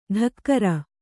♪ ḍhakkara